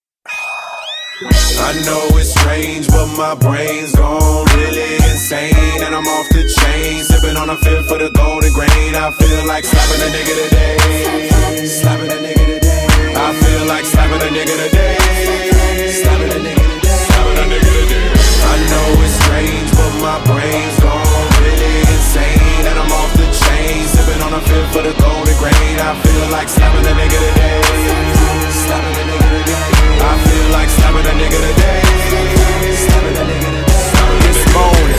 12 Slap